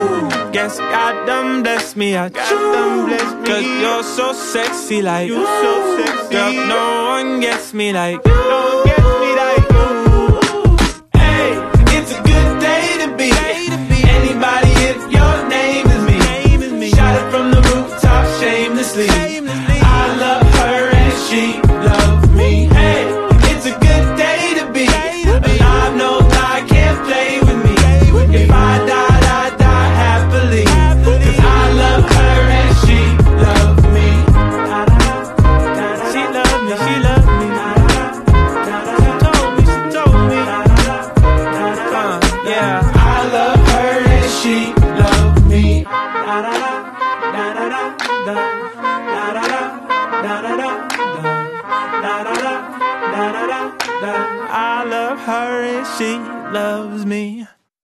Walking on a street, It sound effects free download